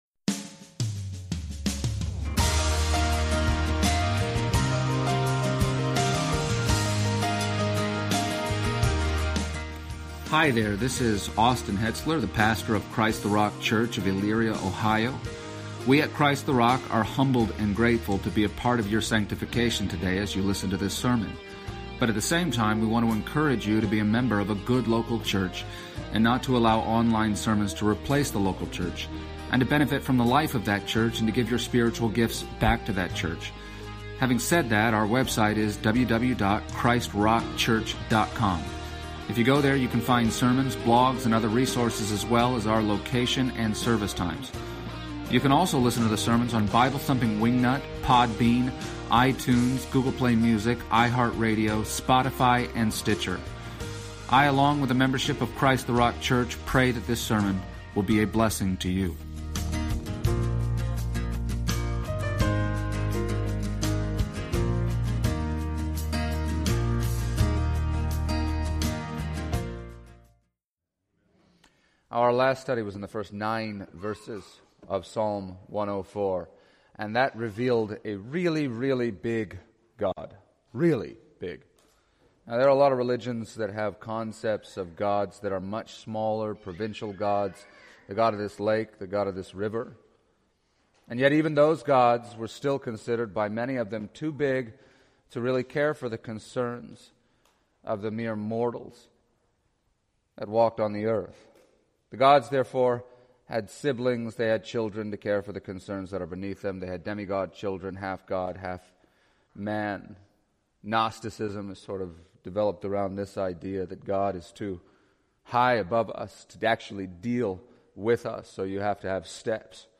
Passage: Psalm 104:10-35 Service Type: Special event